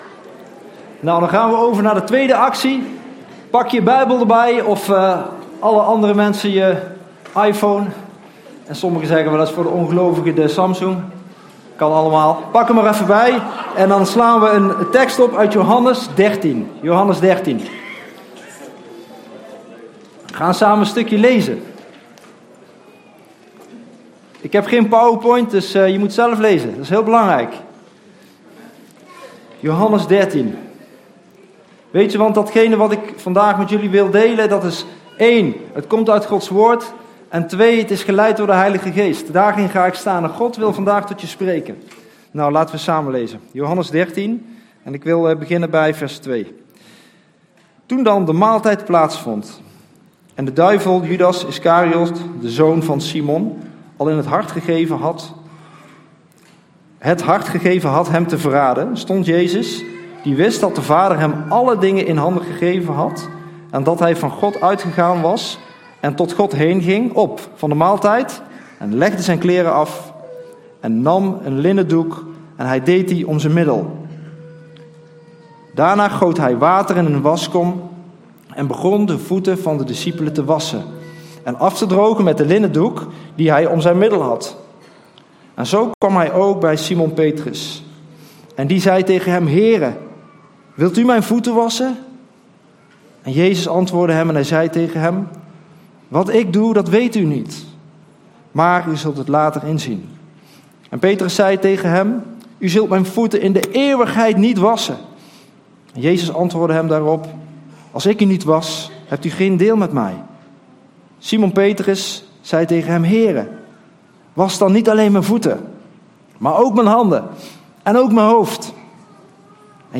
Toespraak 9 april: Jezus is opgestaan!